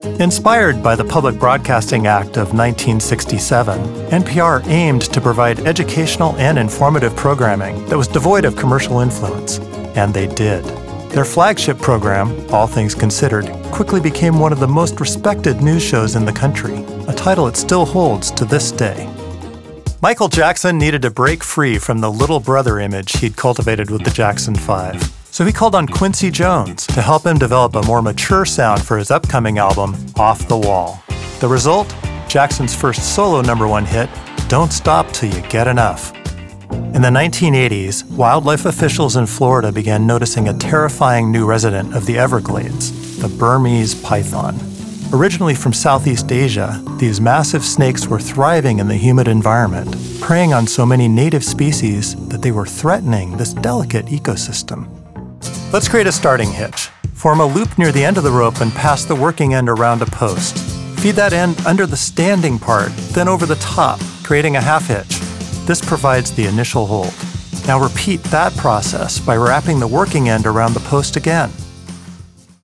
NARRATION DEMO REEL
Narration samples: Documentary narration (news & music), educational narration, e-learning narration
• Quality: professional, ready-to-use recordings with minimum fuss from a broadcast-quality home studio
• Warmth: “a breathy, earthy, folky quality & texture … incredibly approachable”
• Confidence: “soothing yet authoritative and credible”